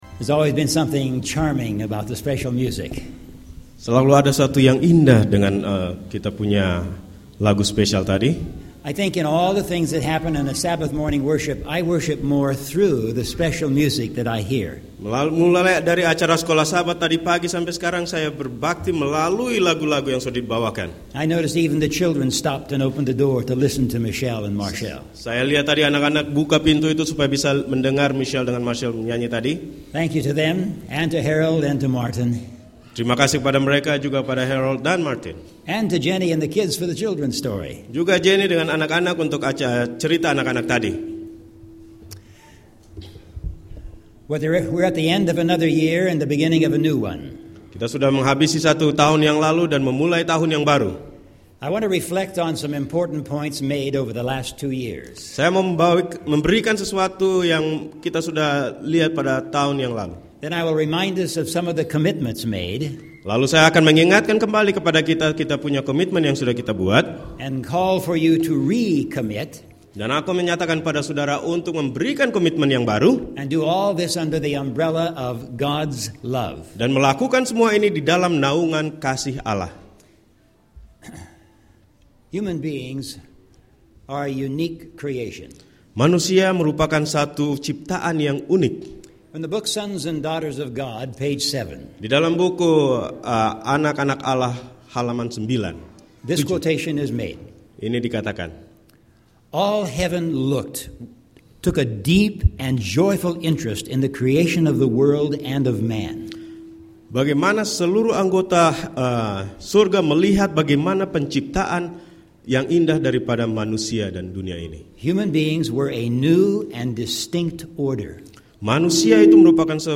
Seventh-day Adventist Church in Bloomington, CA
Audio Sermons